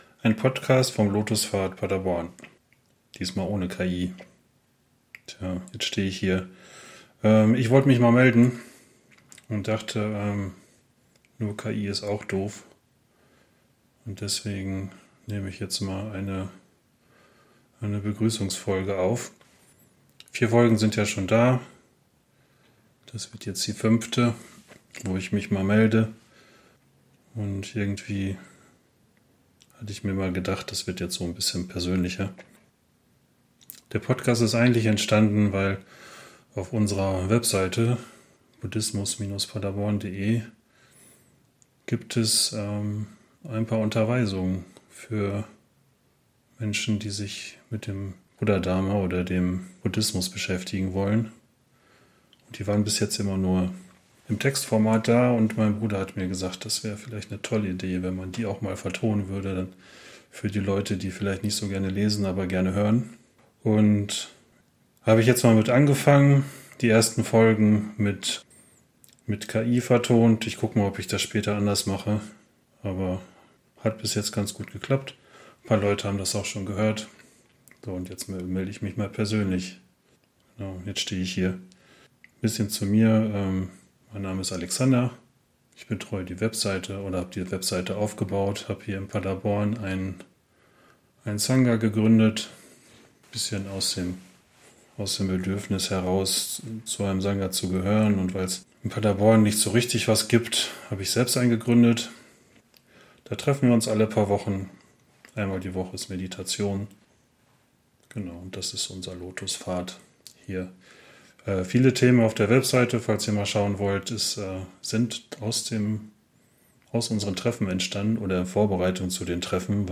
Begrüßung